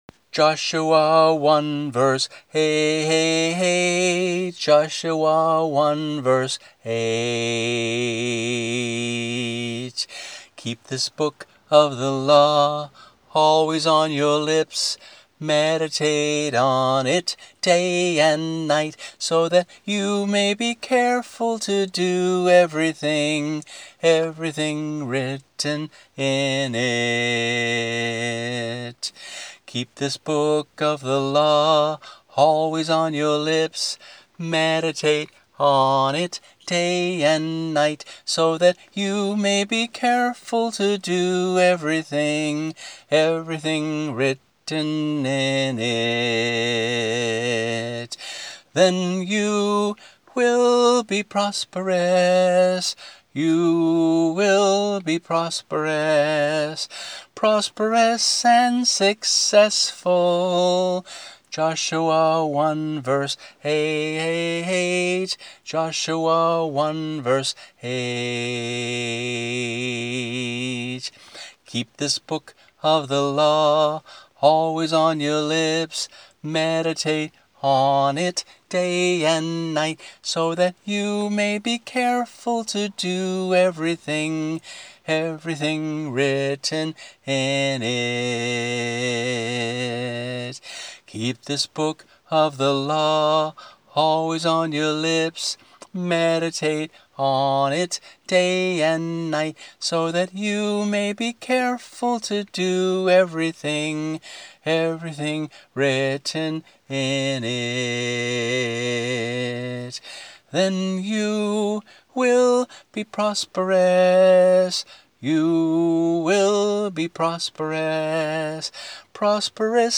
MP3 - voice only